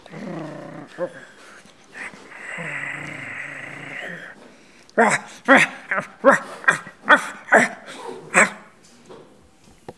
Les sons ont été découpés en morceaux exploitables. 2017-04-10 17:58:57 +02:00 1.7 MiB Raw History
bruit-animal_19.wav